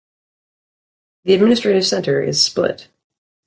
Pronúnciase como (IPA)
/splɪt/